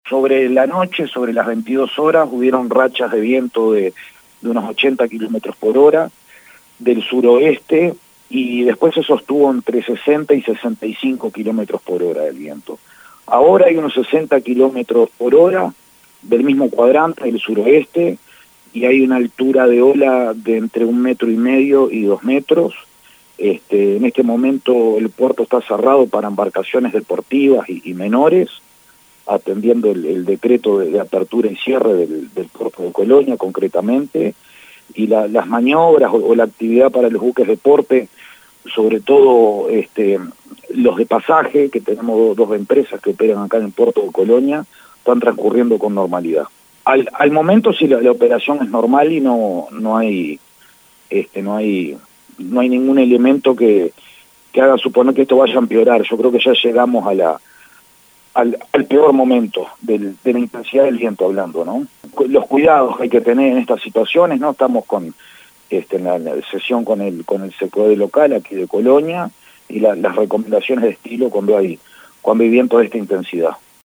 Escuchamos al prefecto Javier Craigdallie.